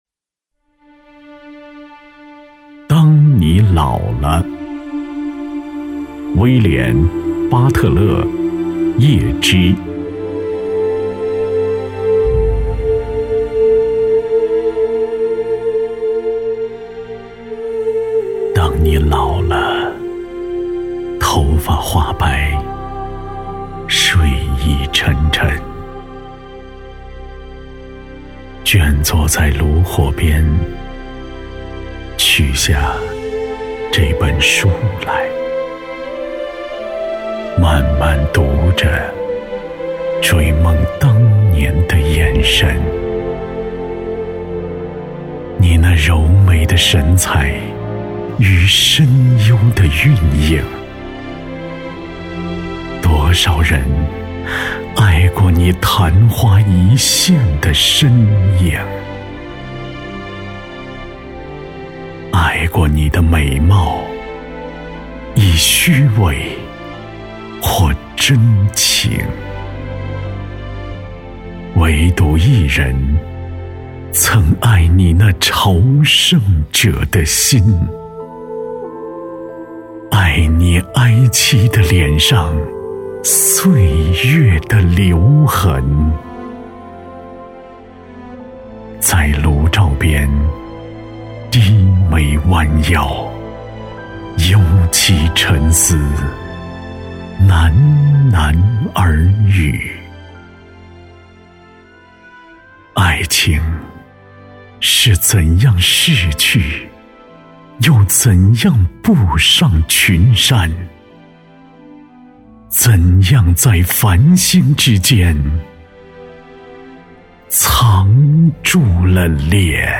配音风格： 成熟 舒缓 大气 沉稳 稳重 亲切